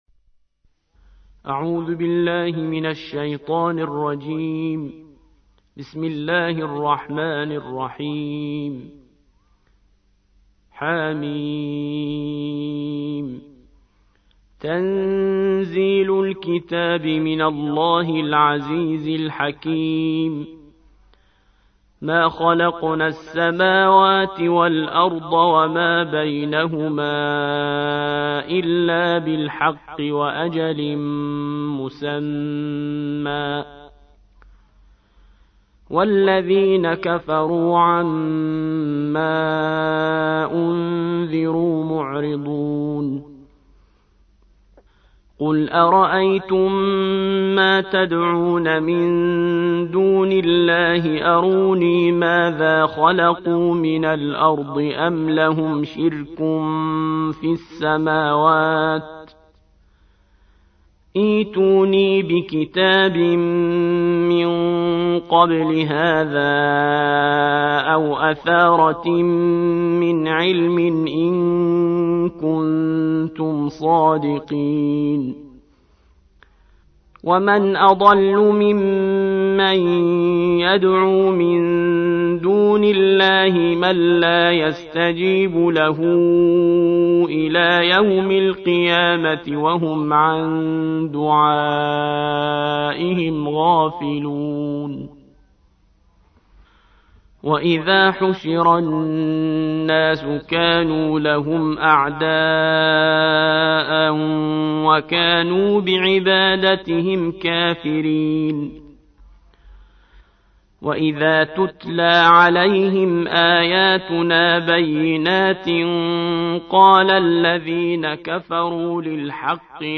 الجزء السادس والعشرون / القارئ